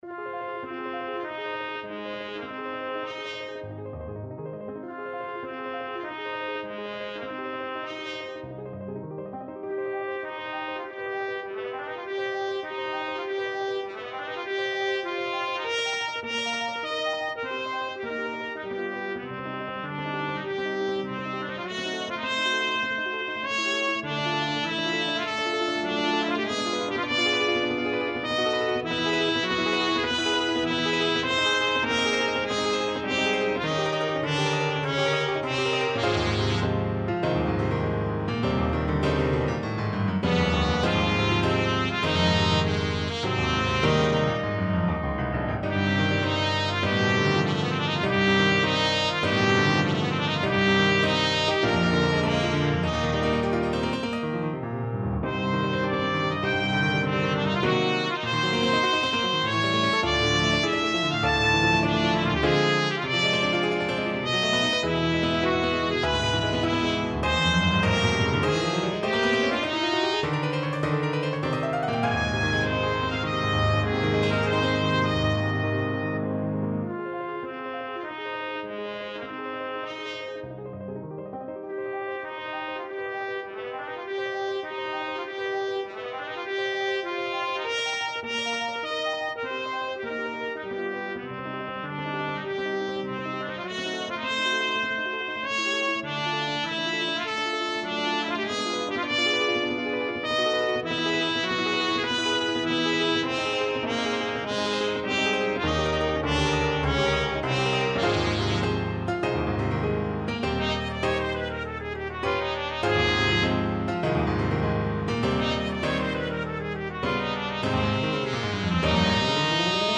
Classical Sinding, Christian Rustling of Spring, Op. 32 No.3 Trumpet version
Trumpet
2/4 (View more 2/4 Music)
G4-A6
Ab major (Sounding Pitch) Bb major (Trumpet in Bb) (View more Ab major Music for Trumpet )
Agitato =100-112
Classical (View more Classical Trumpet Music)